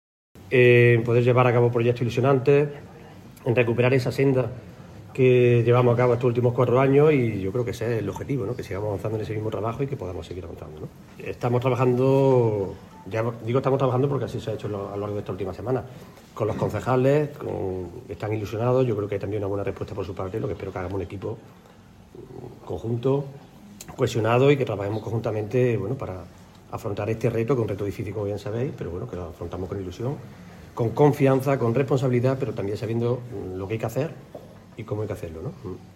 Por su parte, el secretario general del PSOE de la capital y portavoz socialista, Julio Millán, señaló que esta moción es un cambio de gobierno demandado por la ciudadanía, “que la gente nos la pedía en las calles ese cambio, recuperar esa senda que teníamos de avance en estos cuatro años pasados”.
Cortes de sonido